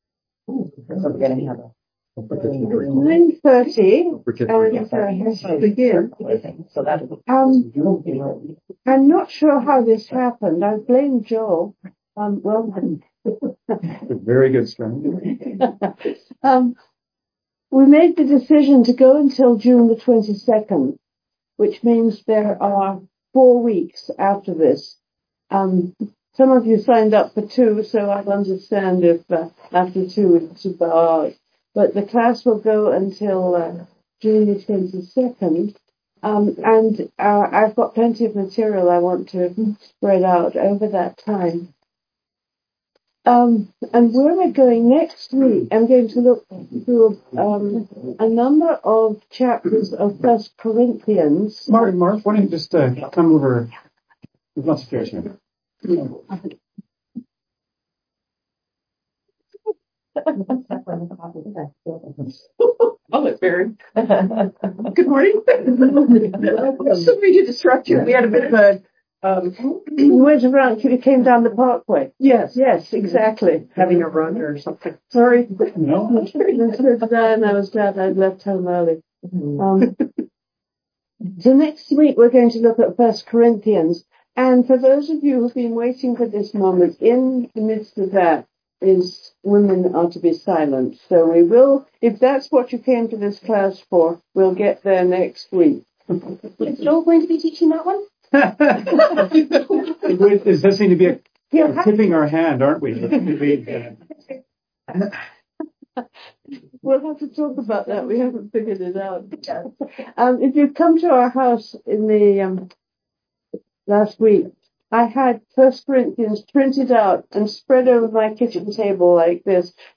New Learning for Life class begins May 4 2025.